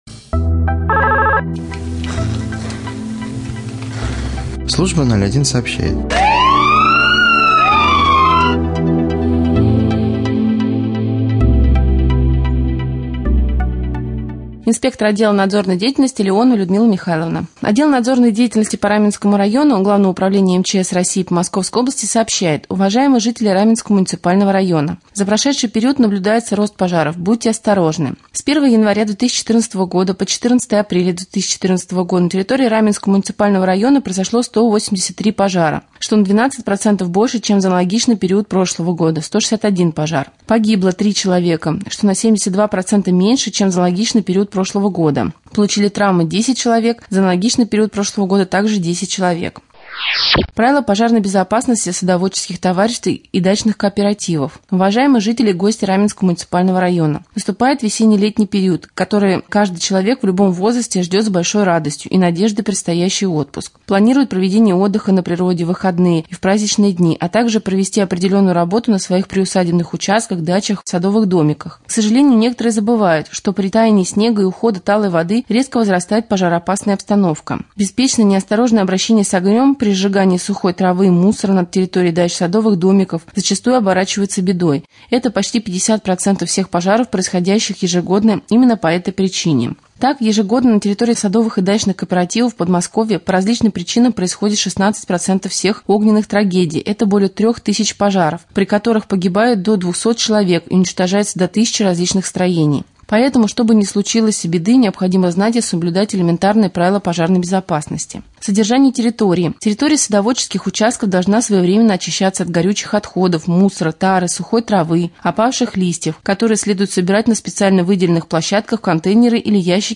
24.04.2014г. в эфире раменского радио - РамМедиа - Раменский муниципальный округ - Раменское